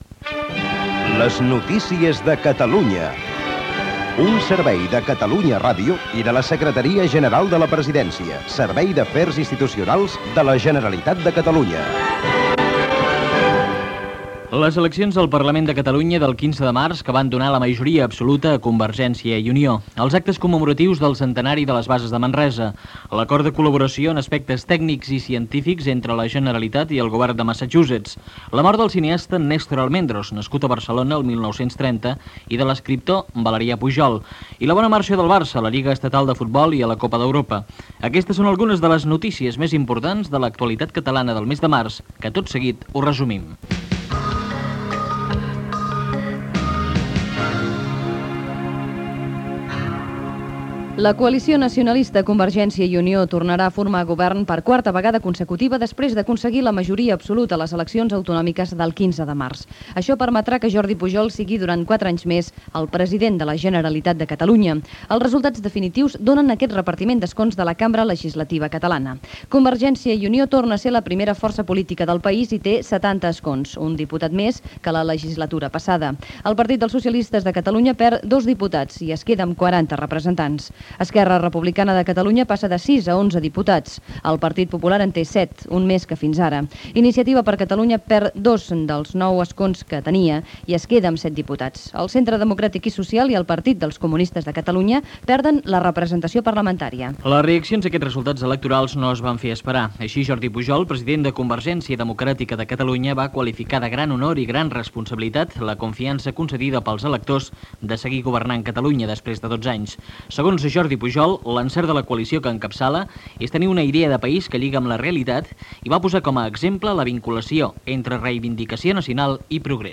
Sumari, resultat de les eleccions al Parlament de Catalunya del 15 de març. Gènere radiofònic Informatiu Data emissió 1992-03 Banda FM Localitat Barcelona Comarca Barcelonès Durada enregistrament 10:51 Idioma Català Notes Es distribuïa a l'estranger enregistrat.